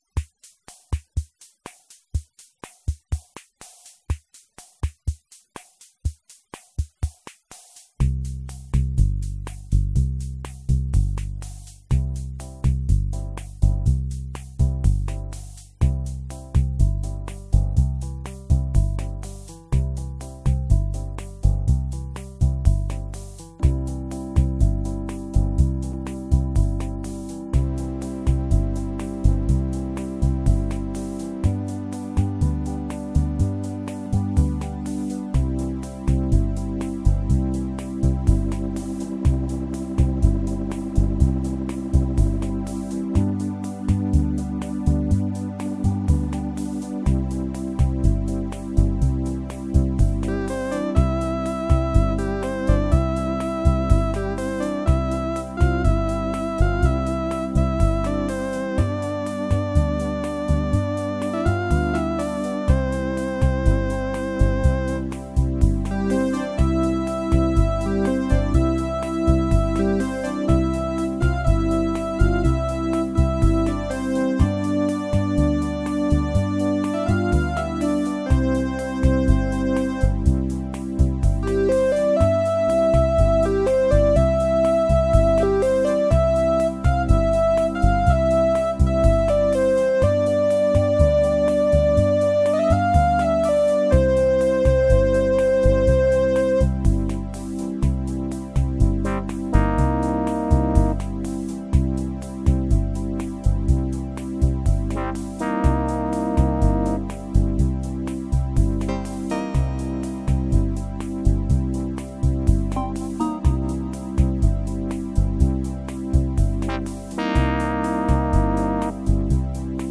Das ist eine einmanualige Orgel mit Akkordeon-Knopfbass und...
Ich denke aber, man hat einen kleinen Einblick, was einen da erwartet: Old-School Sound pur . In die 'Tonne' werde ich das Teil aber erst mal nicht geben, denn irgendwie ist die Kiste so schräg, dass es schon wieder cool ist ;-)